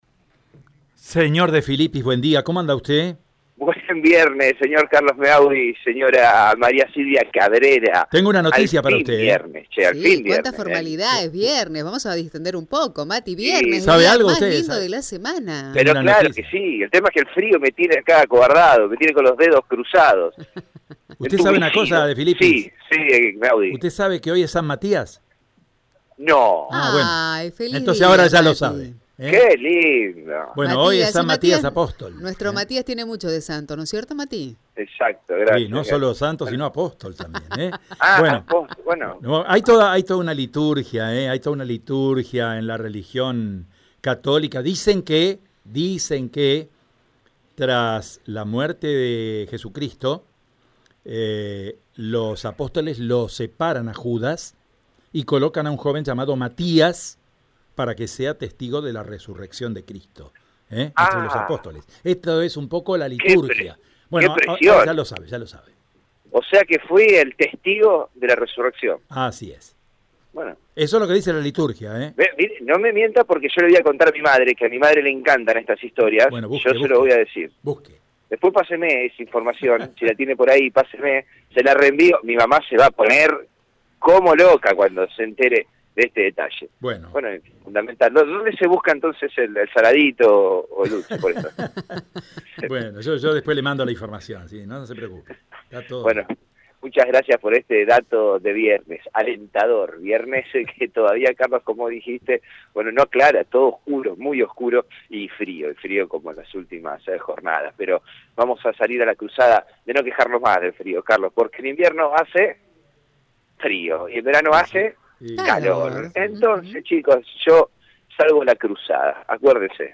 El informe